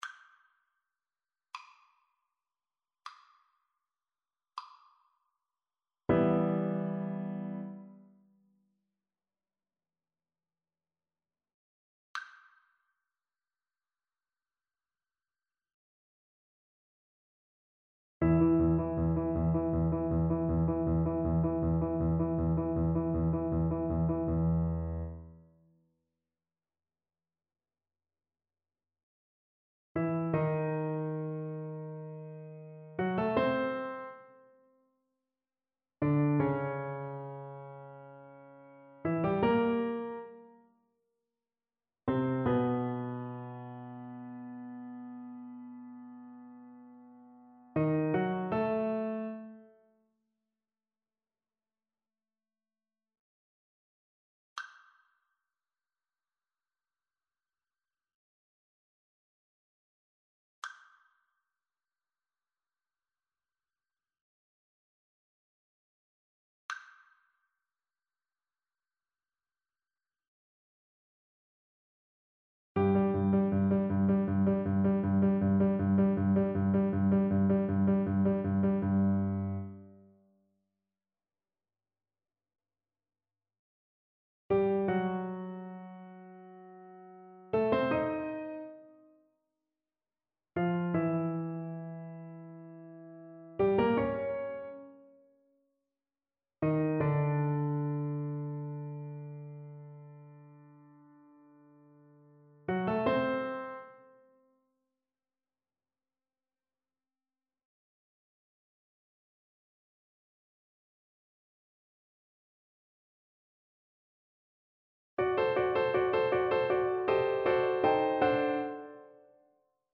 Play (or use space bar on your keyboard) Pause Music Playalong - Piano Accompaniment Playalong Band Accompaniment not yet available reset tempo print settings full screen
Asile héréditaire, Arnold's aria from Guillaume Tell
G major (Sounding Pitch) (View more G major Music for Voice )
4/4 (View more 4/4 Music)
Andantino =60 (View more music marked Andantino)
Classical (View more Classical Voice Music)